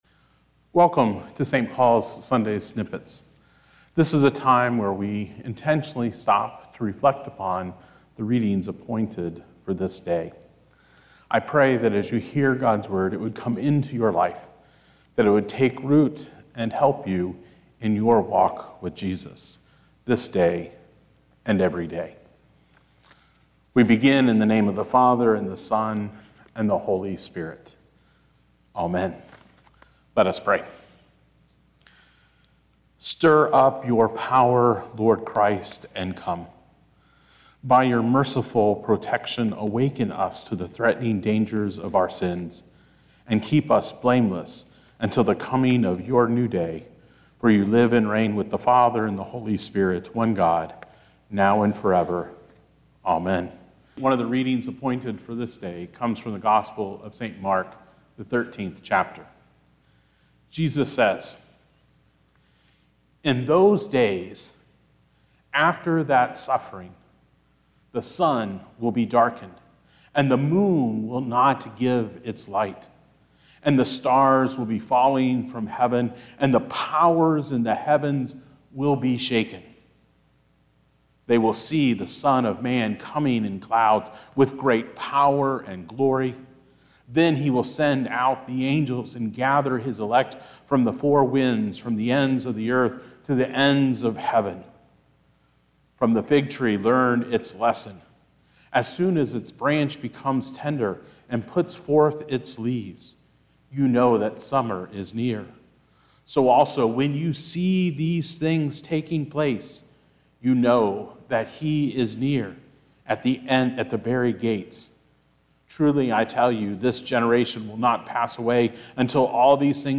Recorded by the staff and volunteers at St. Paul Lutheran Church - Borchers in Seymour, Indiana.